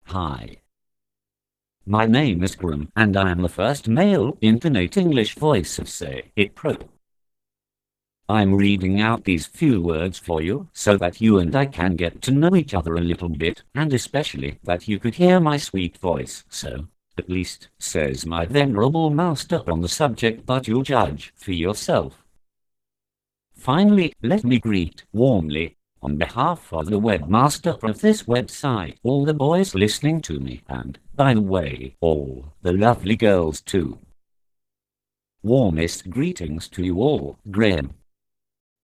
Texte de démonstration lu par Graham, première voix masculine anglaise de LogiSys SayItPro (Version 1.70)